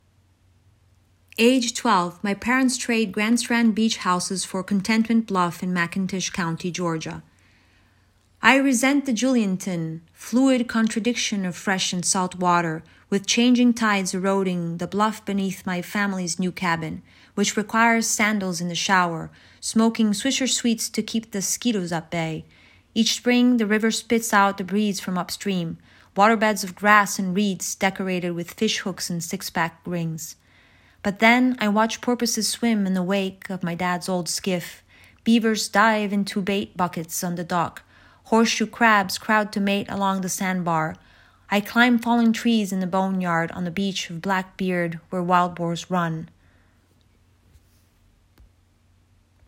readings from our Online Podcast: